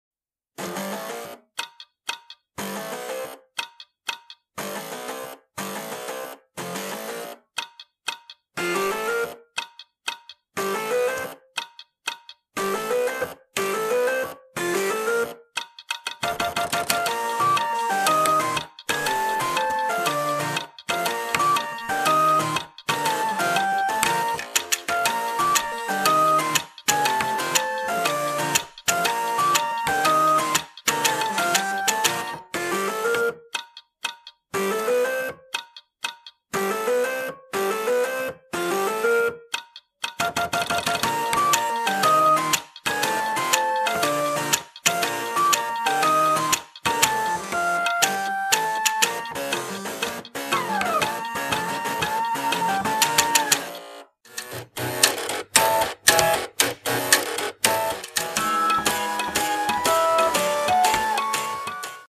computer hardware orchestra